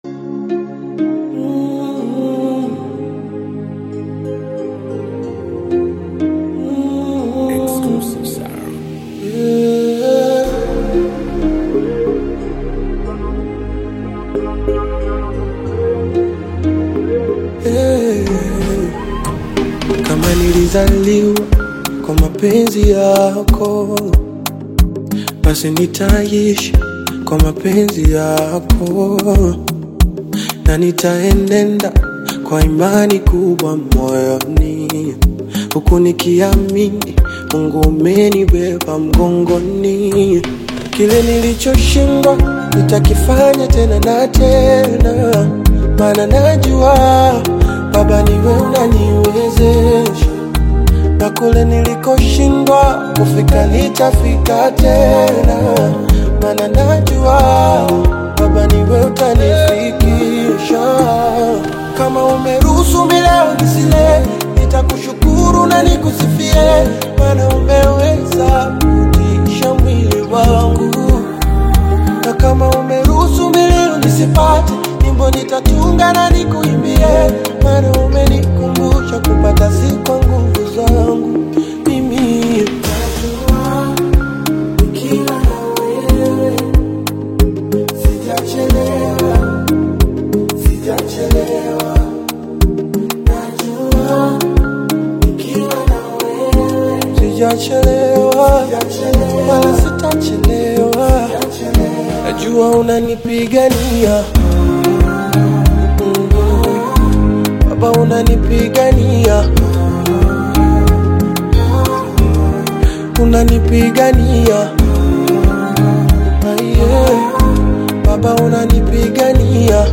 soulful gospel single
Genre: Gospel